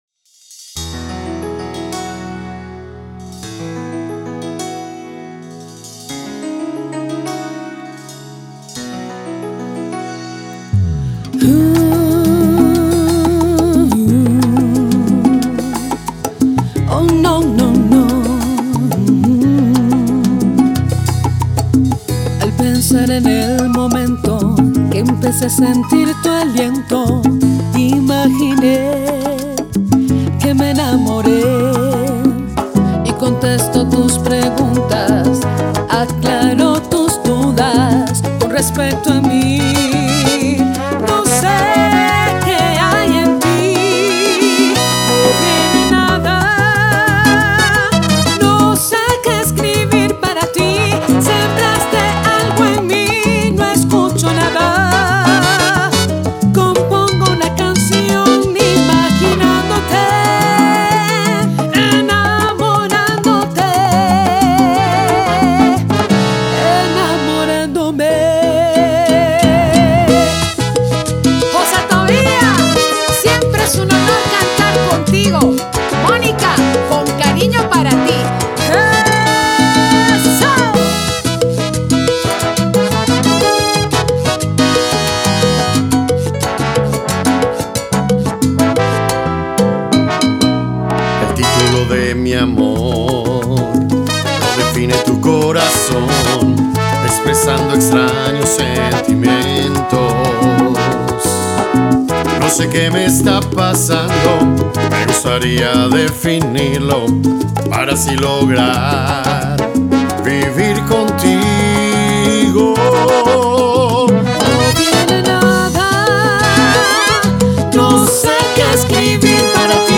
una mezcla de sensualidad, alegría y sabor latino.